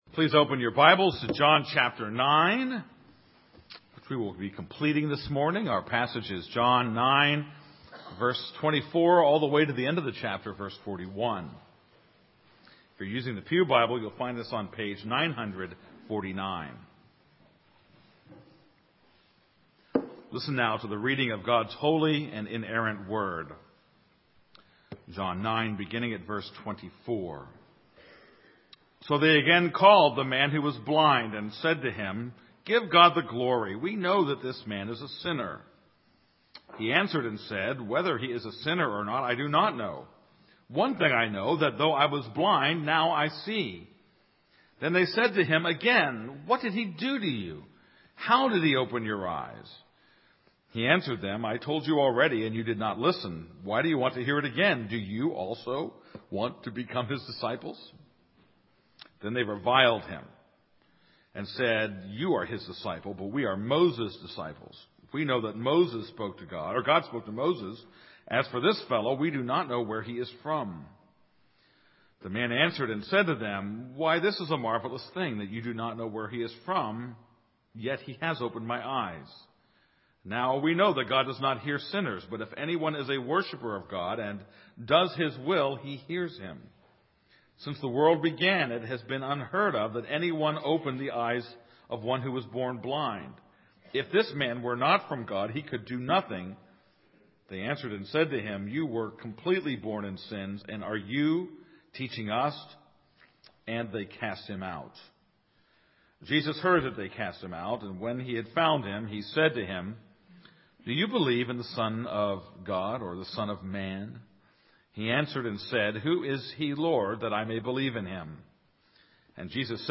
This is a sermon on John 9:24-41.